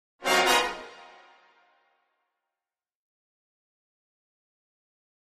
Brass Section, Short Reminder, Type 2 - Double, Finale